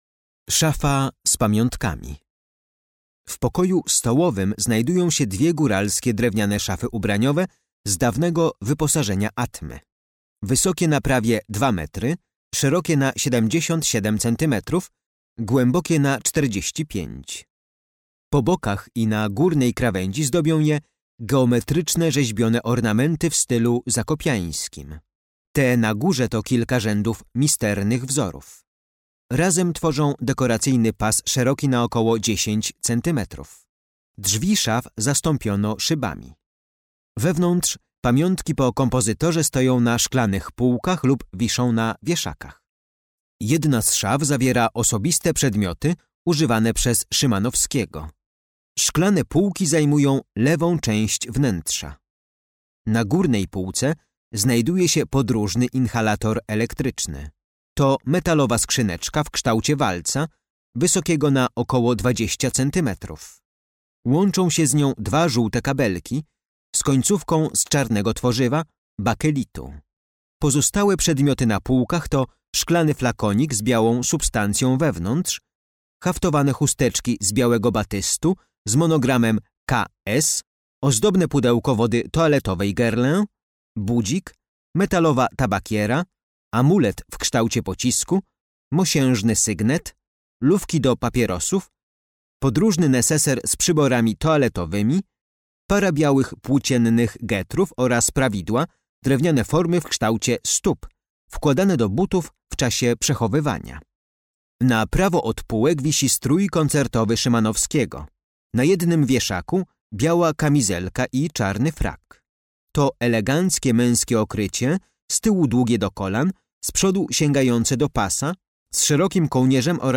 Audiodeskrypcja dla wybranych eksponatów z kolekcji MNK znajdujących się w Muzeum Karola Szymanowskiego w willi "Atma" w Zakopanem.